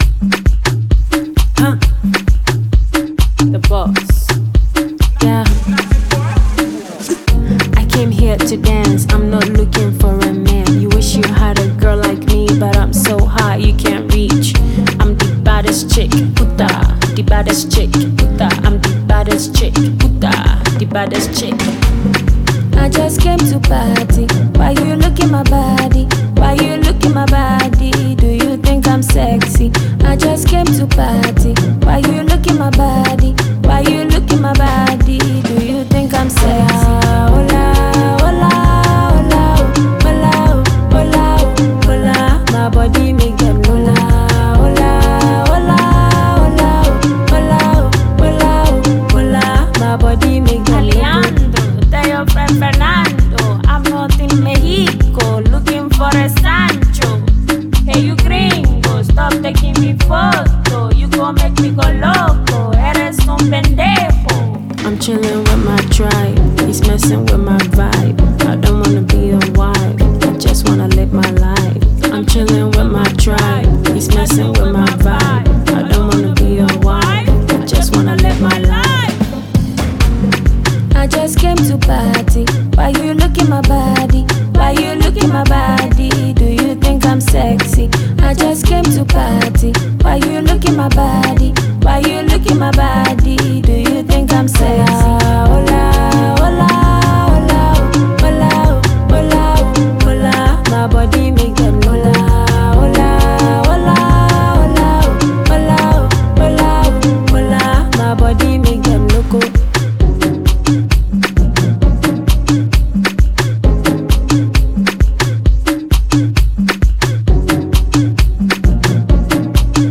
Genre: Afrobeat / Soul